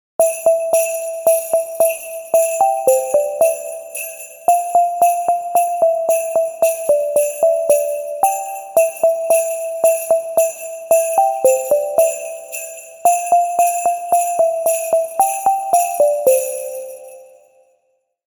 Christmas Jingle Bells Notification Melody
A short Christmas jingle bell notification melody creates a cheerful holiday mood. This festive notification sound works perfectly for phone alerts, app notifications, ringtones, and UI sounds. Bright jingle bells make this Christmas notification melody ideal for holiday apps and seasonal projects.
Genres: Sound Effects
Christmas-jingle-bells-notification-melody.mp3